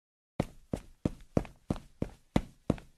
footsteps.ogg